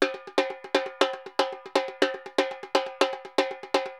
Repique Baion 120_1.wav